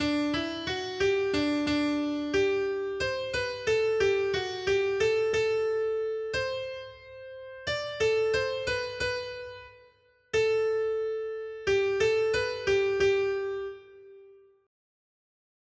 Noty Štítky, zpěvníky ol274.pdf responsoriální žalm Žaltář (Olejník) 274 Ž 97, 1-2 Ž 97, 5-6 Ž 97, 9 Skrýt akordy R: Hospodin kraluje, je povznesen nad celou zemí. 1.